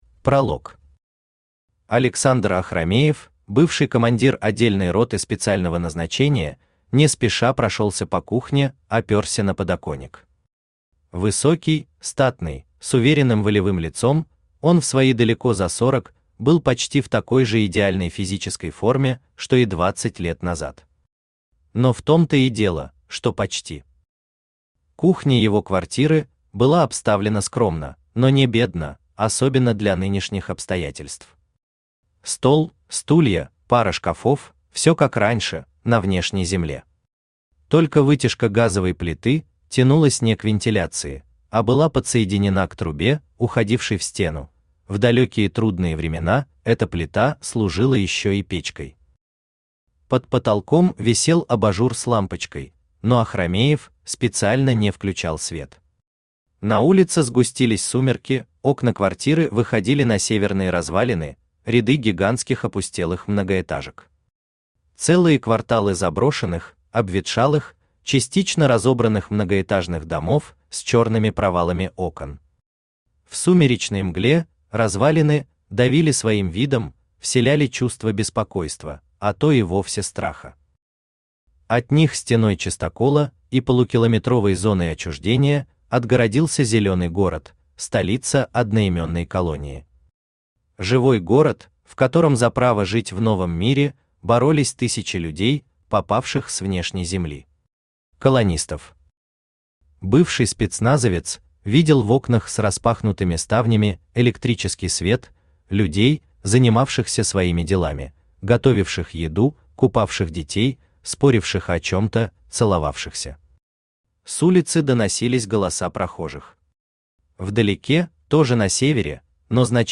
Аудиокнига Командир | Библиотека аудиокниг
Aудиокнига Командир Автор Павел Мамонтов Читает аудиокнигу Авточтец ЛитРес.